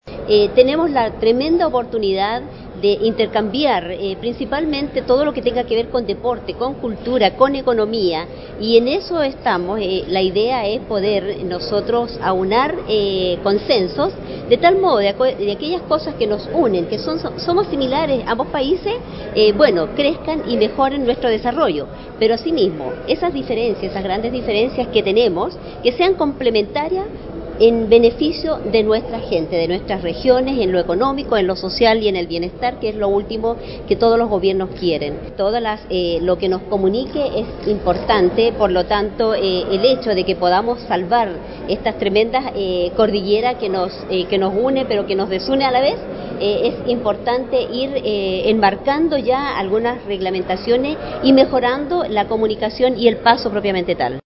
Berta Torres Licuime, gobernadora chilena de Huasco Luis Beder Herrera, gobernador de La RiojaDSC_4234
gobernadora-de-huasco.mp3